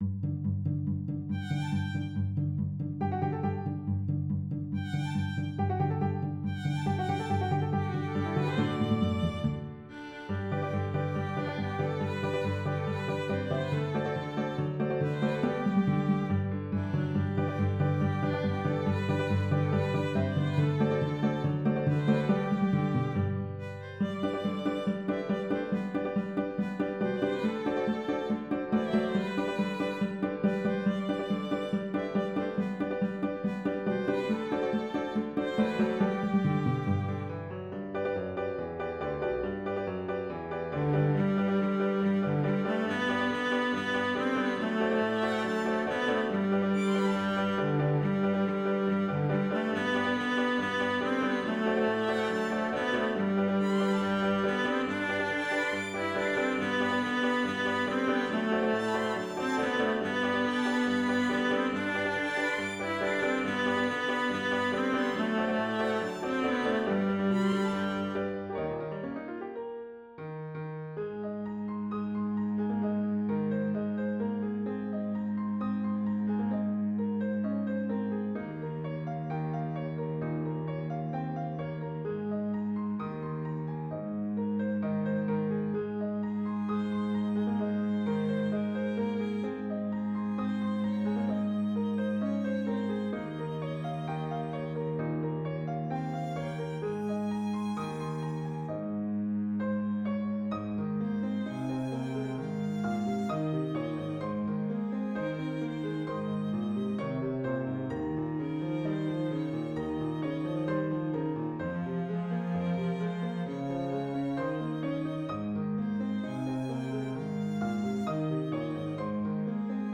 Piano, Violin, Cello
Christian, Gospel, Sacred, Praise & Worship.